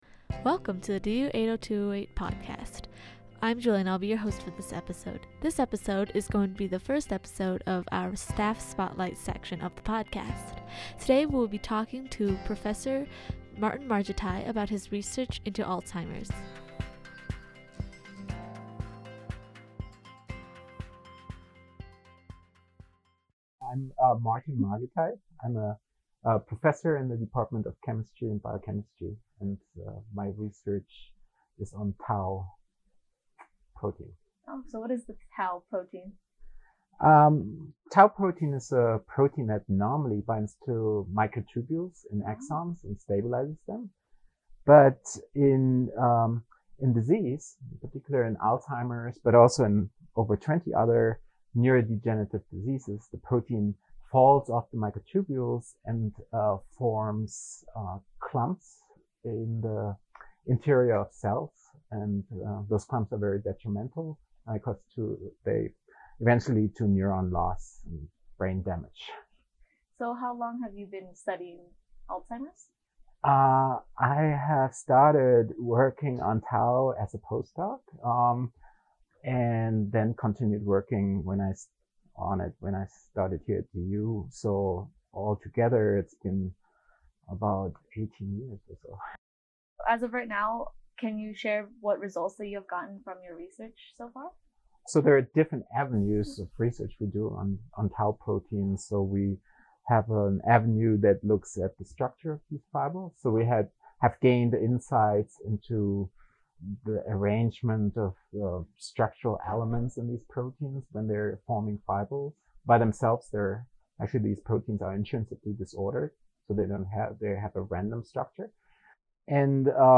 Contributions: Special guest